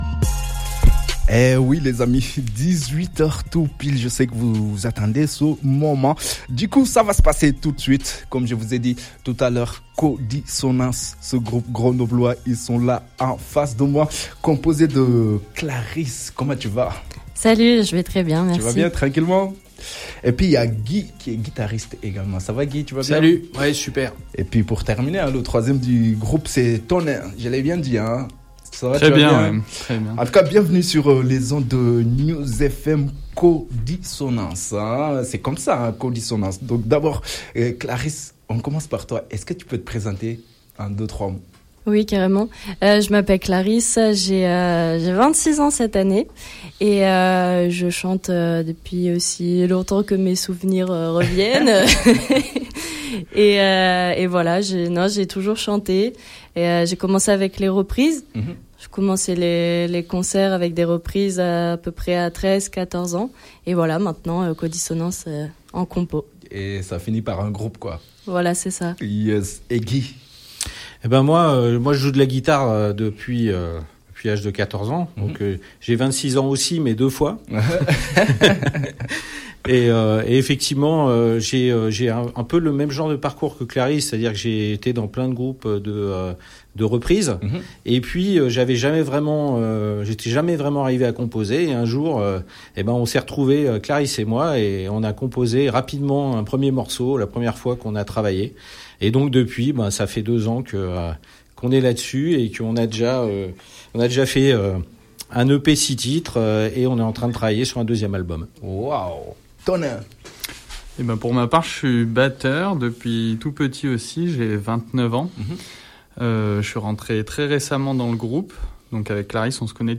Guitare - voix - percussions